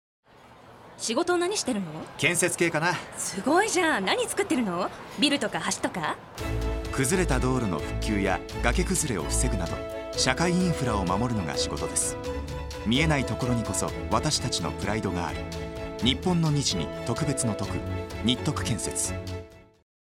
ラジオCMの新バージョン「お仕事編」を放送中です
当社は2025年4月1日より、ニッポン放送「オールナイトニッポン」の番組内でラジオCMを放送しています。